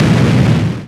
Título: Explosão
Palavras-chave: Jogos educacionais; Foley
Resumo: Som de explosão de bomba.
explosão.wav